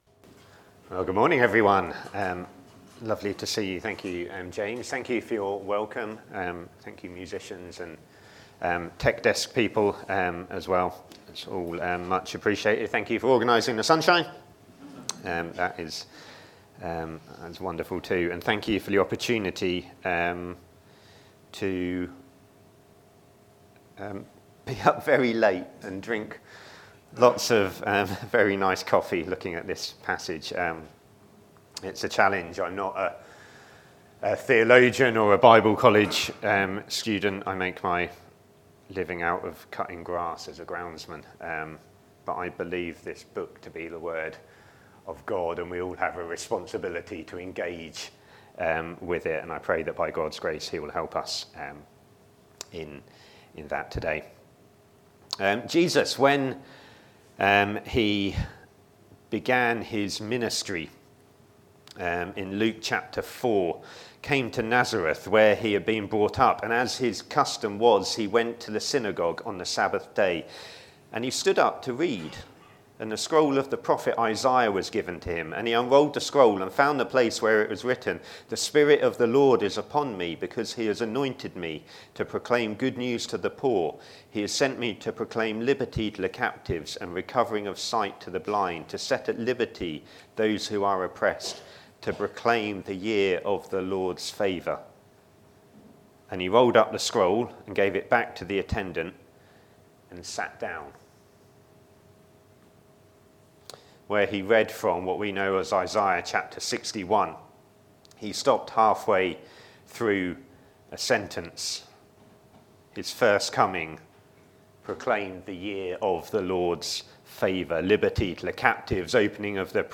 Media for a.m. Service on Sun 09th Mar 2025 10:30
Theme: Sermon